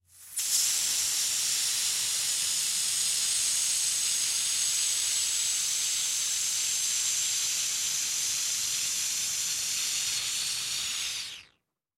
Звук спуска колеса